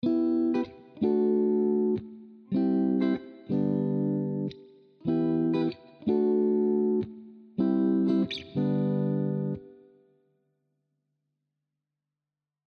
chiqui-audio---strato-wet-chor.mp3